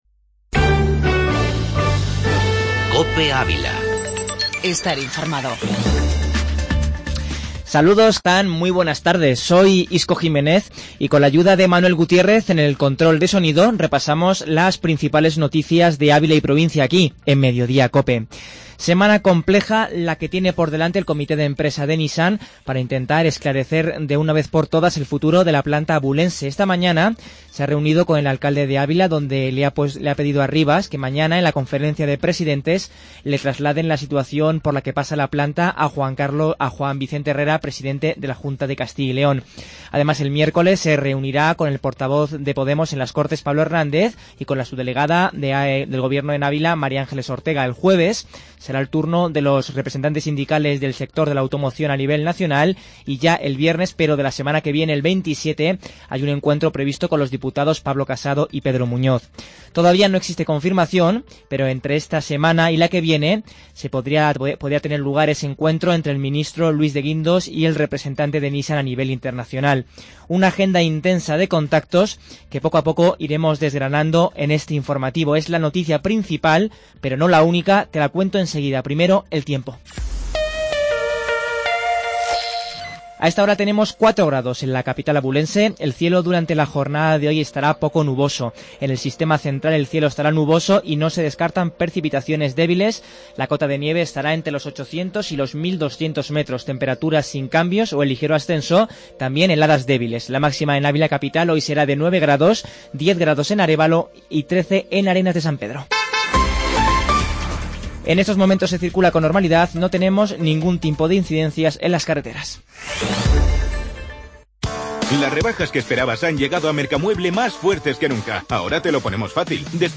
Informativo Mediodía Cope en Avila